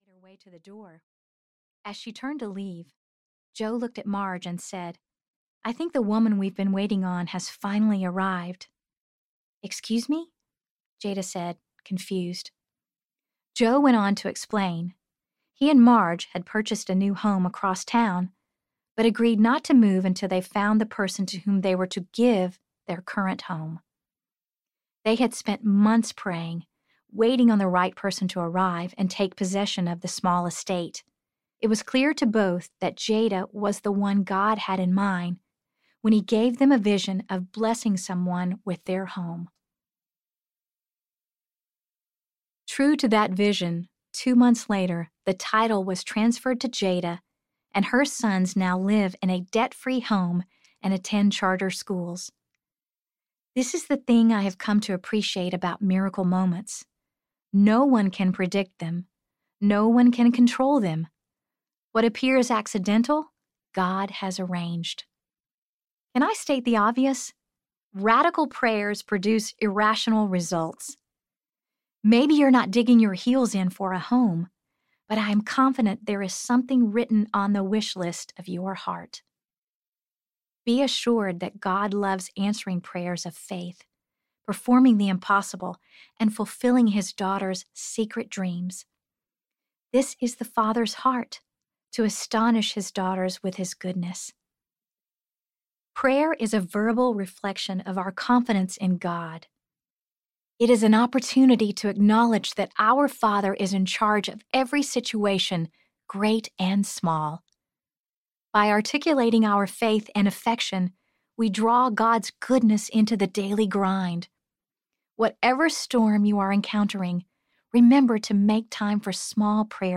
Becoming Brave Audiobook
– Unabridged